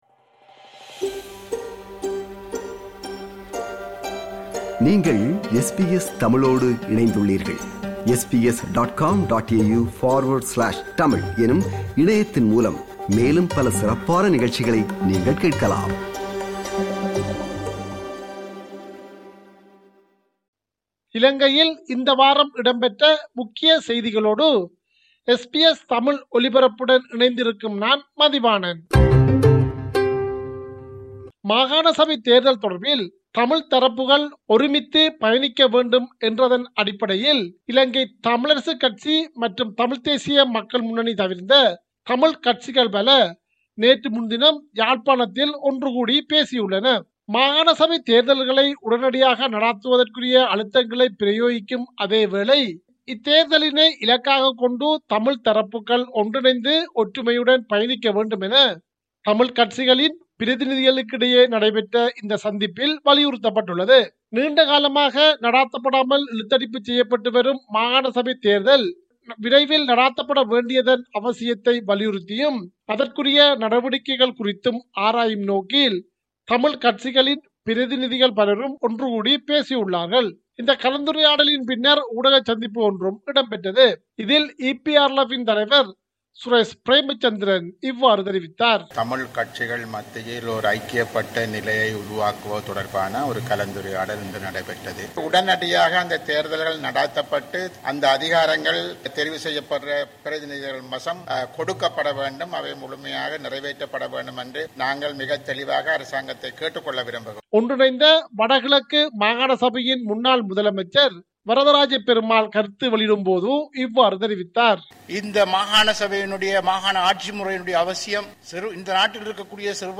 இலங்கை: இந்த வார முக்கிய செய்திகள்
Top news from Sri Lanka this week To hear more podcasts from SBS Tamil, subscribe to our podcast collection.